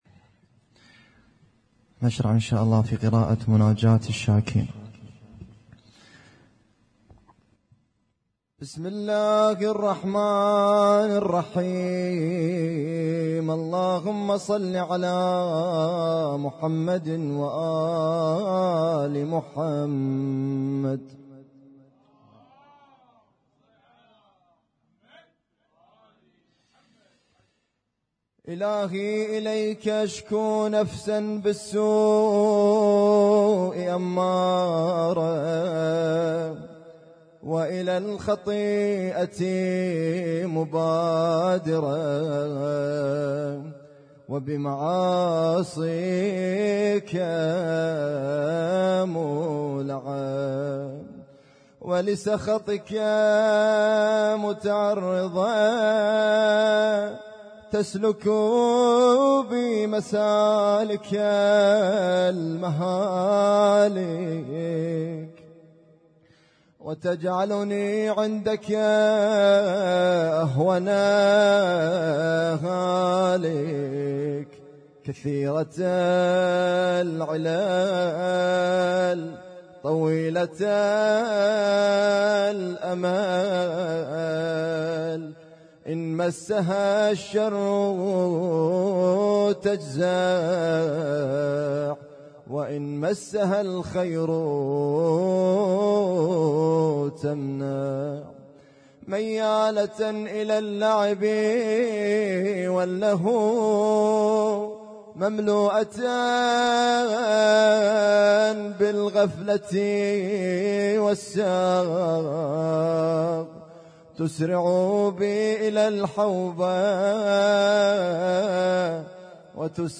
القارئ: الرادود
اسم التصنيف: المـكتبة الصــوتيه >> الصوتيات المتنوعة >> المناجاة